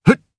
Kibera-Vox_Jump_jp.wav